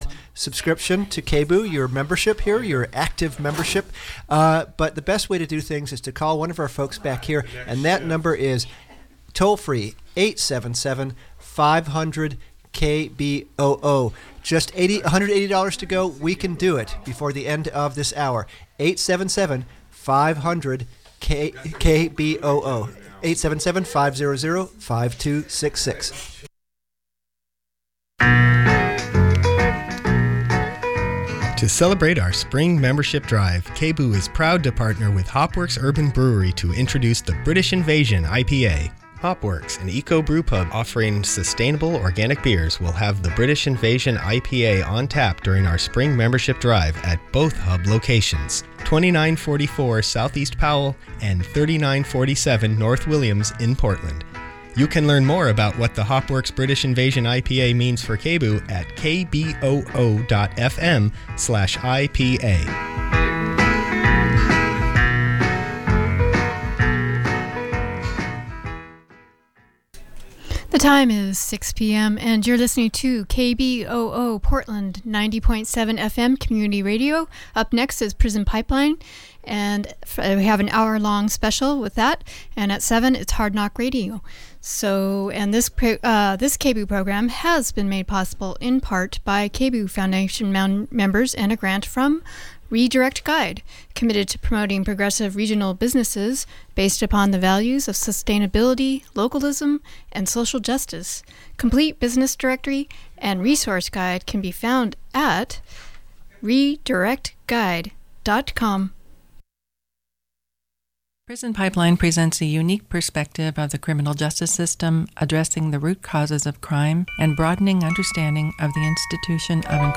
Download audio file In April 2014 women incarcerated at Coffee Creek Correctional Facility shared their deeply held beliefs during an event called This I Believe. This was the second annual This I Believe essay reading held at CCCF, Oregon’s only women’s prison.